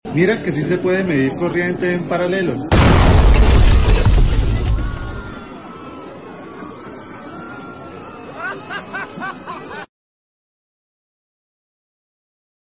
Testing Electronics circuit at Lab, sound effects free download
Testing Electronics circuit at Lab, a capacitor blast